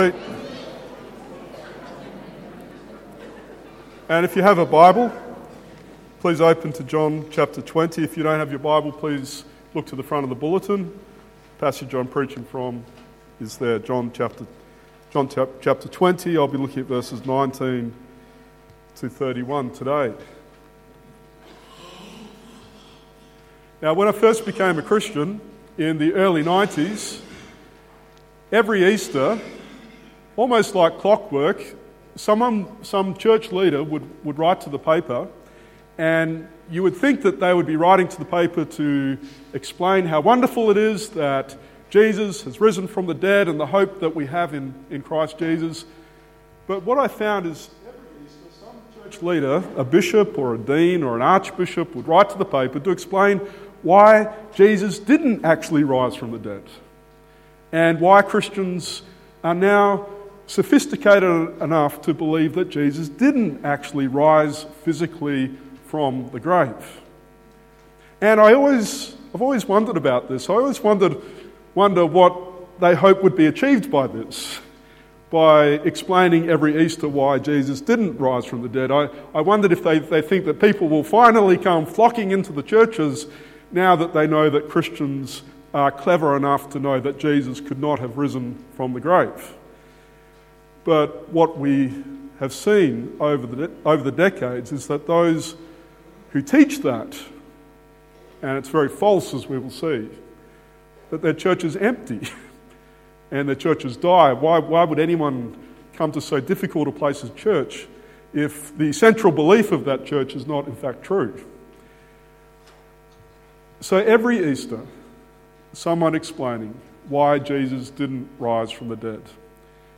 Easter Sunday 2017 | Cornerstone Hobart